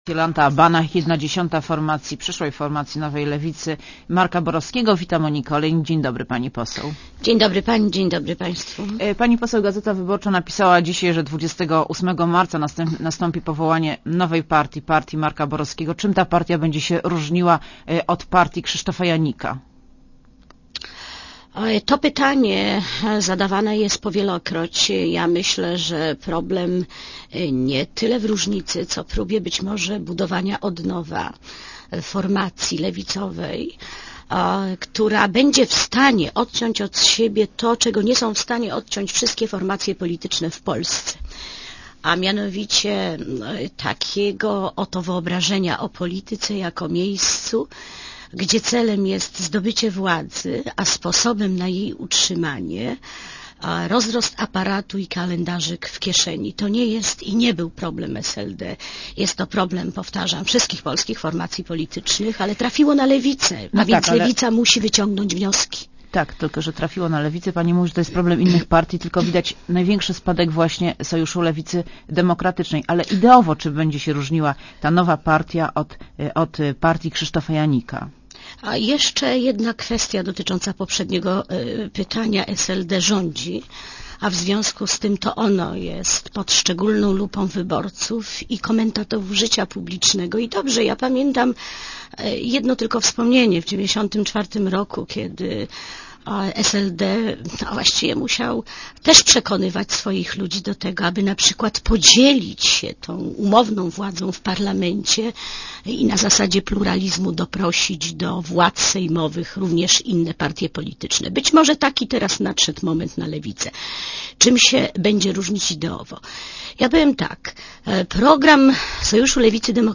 Gościem Radia Zet jest Jolanta Banach, jedna dziesiąta przyszłej formacji nowej lewicy Marka Borowskiego.